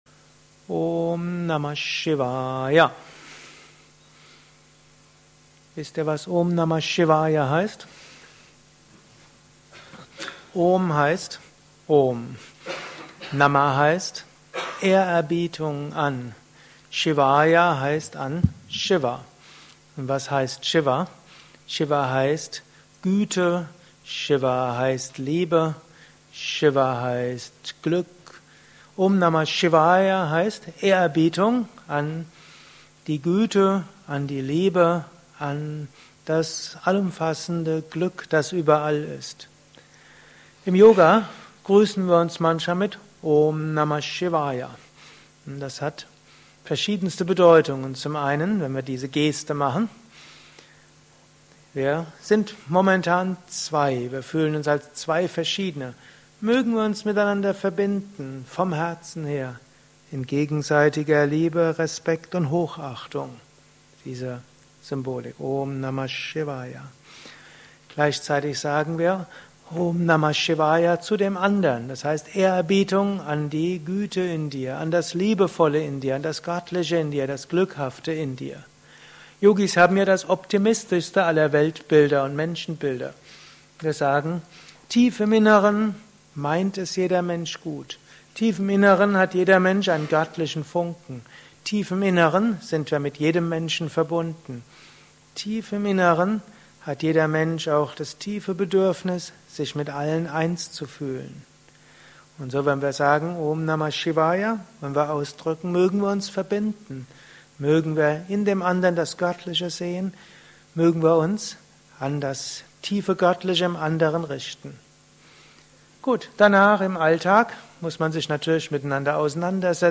Gelesen im Anschluss nach einer Meditation im Haus Yoga Vidya Bad Meinberg.
Lausche einem Vortrag über: Gruss von Om Namah Shivaya